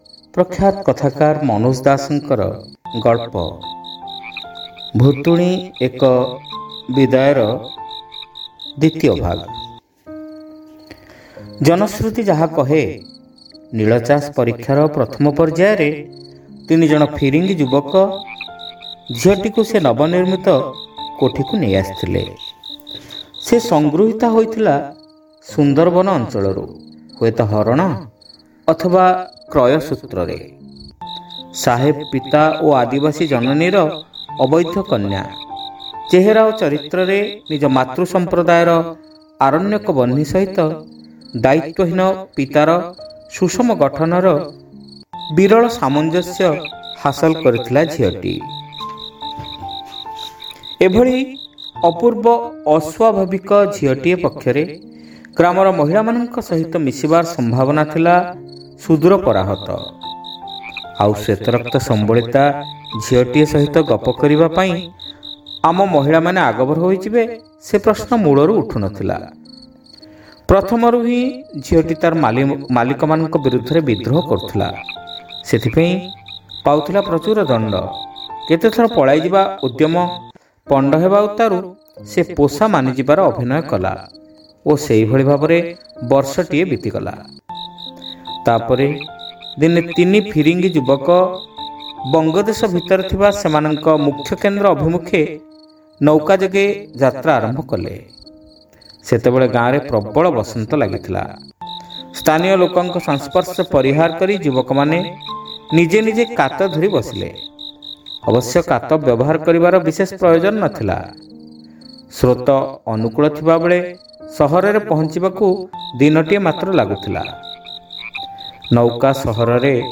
ଶ୍ରାବ୍ୟ ଗଳ୍ପ : ଭୁତୁଣୀ ଏକ ବିଦାୟ (ଦ୍ୱିତୀୟ ଭାଗ)